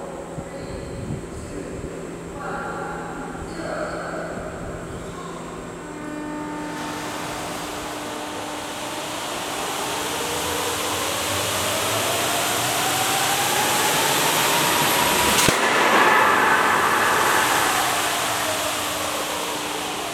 新能源火烧试验声音.WAV